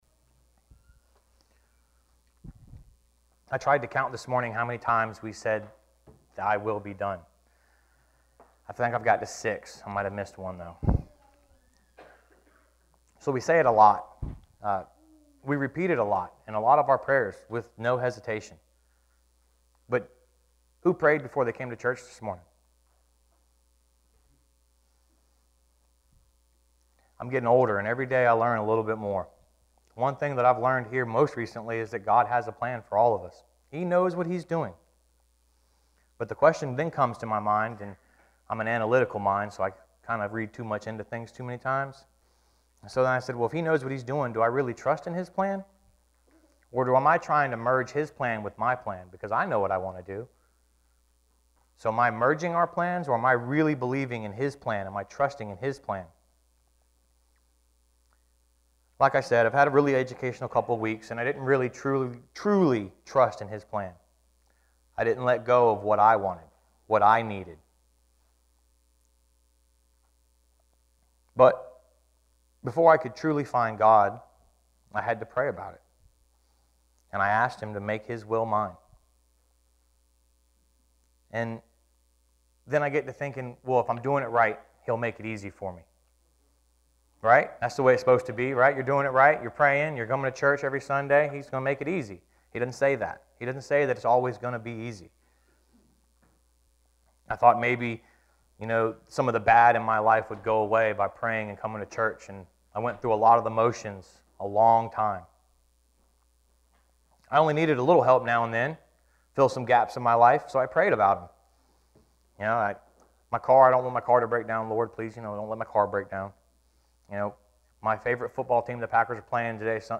This weeks scripture and sermon:
10-16-sermon.mp3